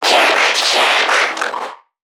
NPC_Creatures_Vocalisations_Infected [96].wav